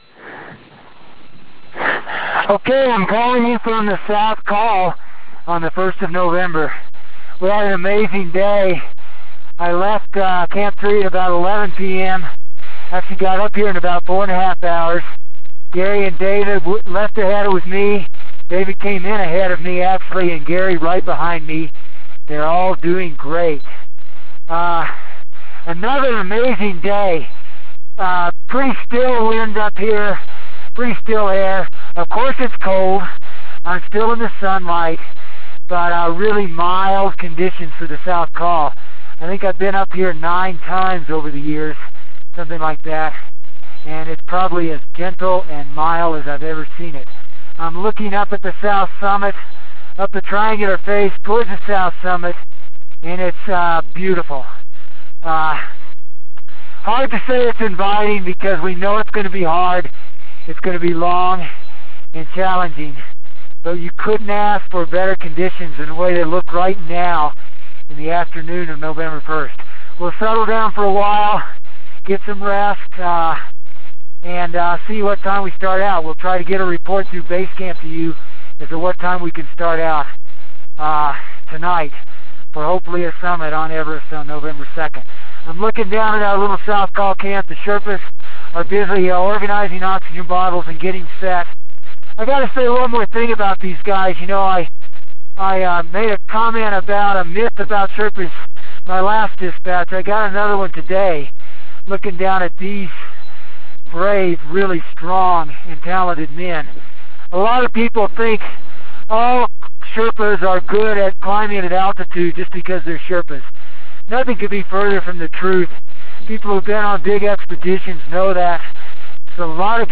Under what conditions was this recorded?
November 1 – Team feeling great at the South Col. Ready to begin summit bid!